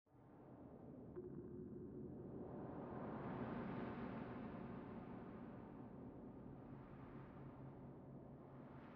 WindLoop.wav